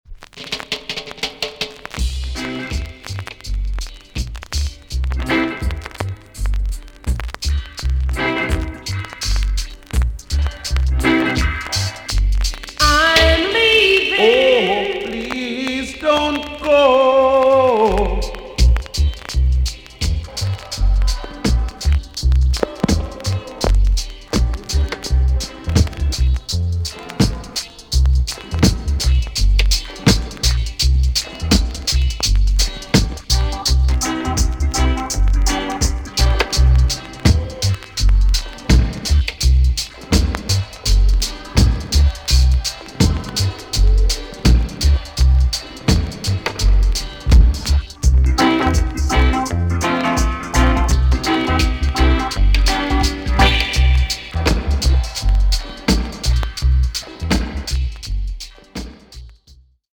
B.SIDE Version
VG+~VG ok 軽いチリノイズがあります。